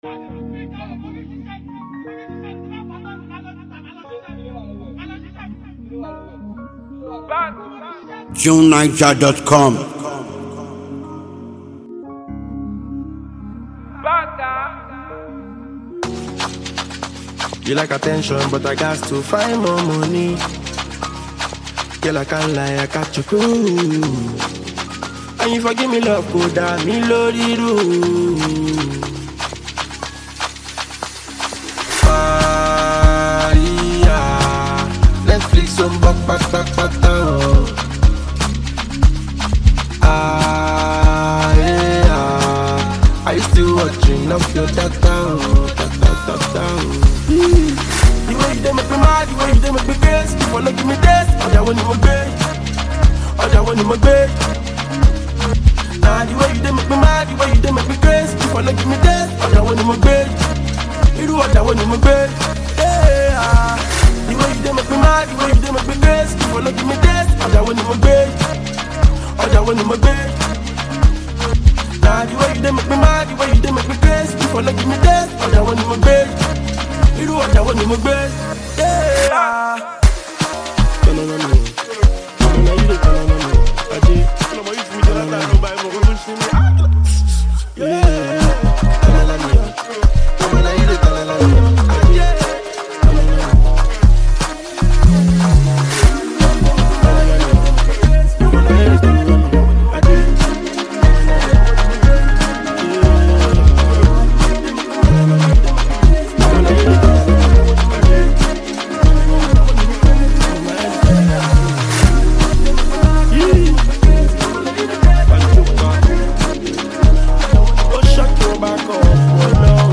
throbbing tune